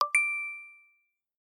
paymentsuccess.mp3